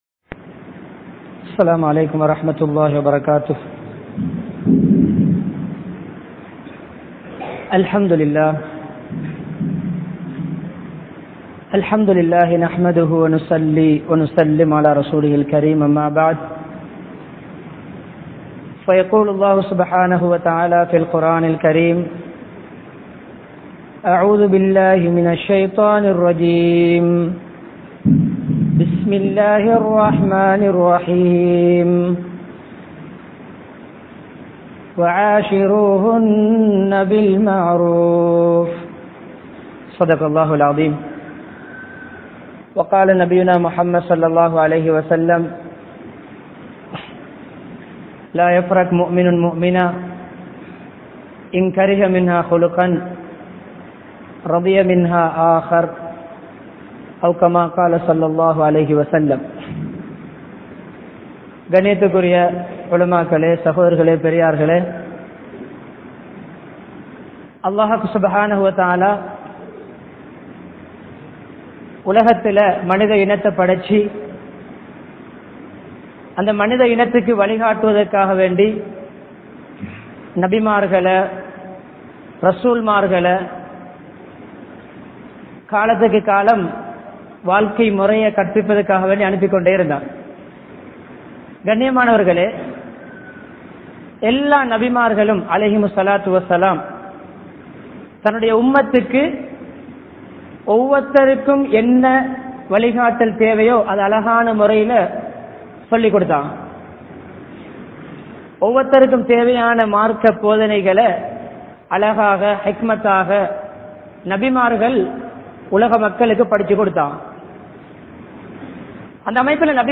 Panadura, Gorakana Jumuah Masjith